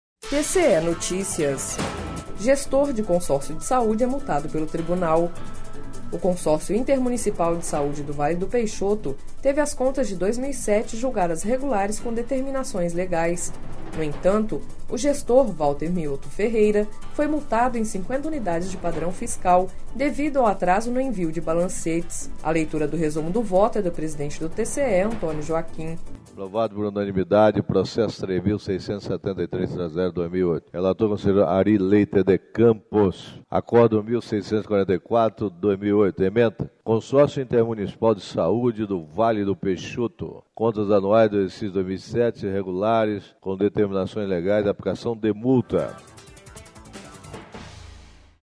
A leitura do resumo do voto é do presidente do TCE-MT, Antonio Joaquim.//
Sonora: Antonio Joaquim – conselheiro presidente do TCE-MT